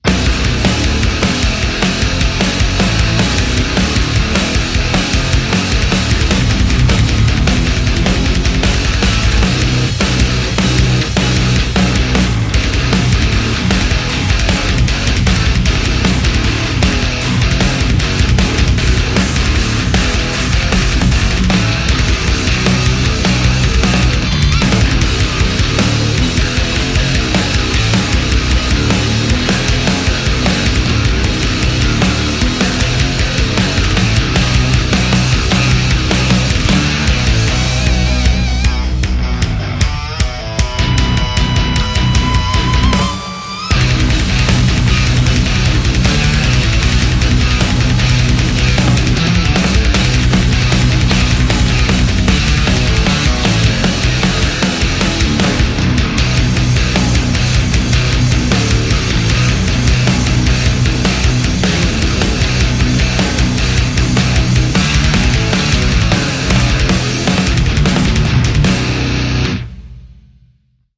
Shreddage 2 IBZ的升级版，一把日式7弦电吉他，声音明亮刺激，适合金属风格。
40种现成的音色快照，从高增益放大到清晰、空灵的演奏